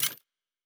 Basic Attack Tick.wav